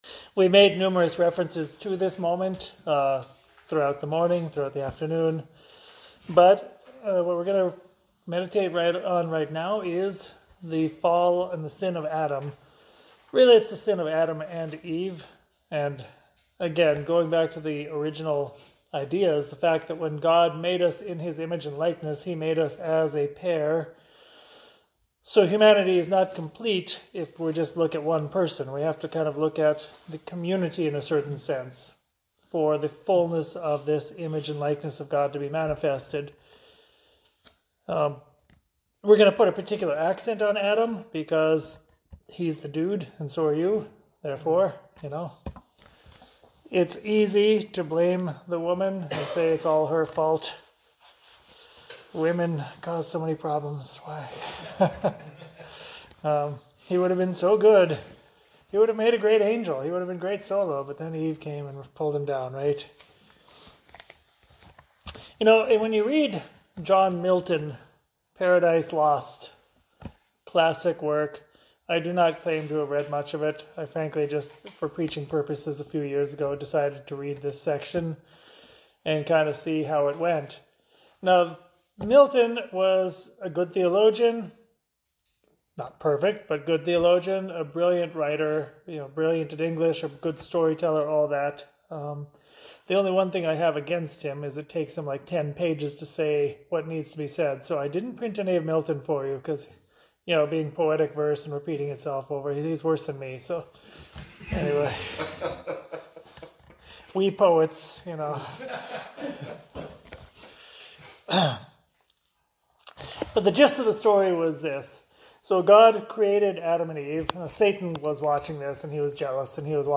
meditation from the Men’s Spiritual Exercise retreat